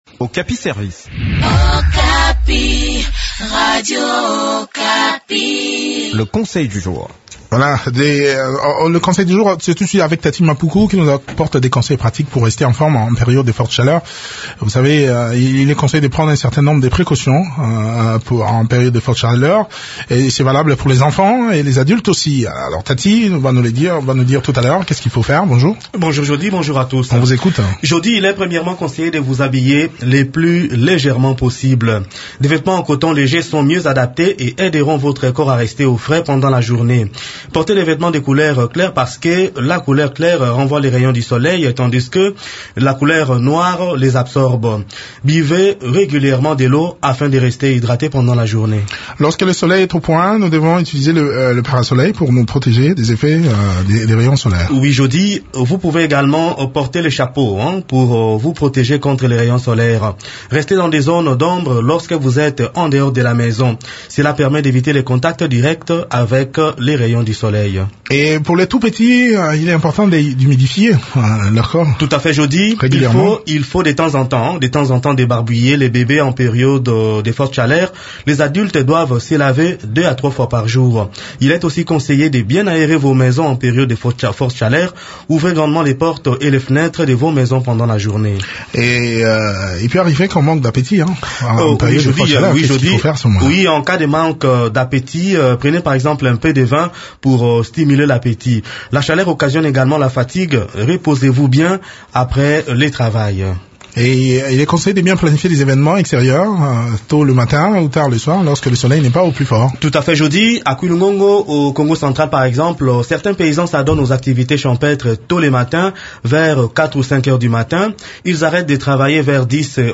Découvrez ces précautions dans cette chronique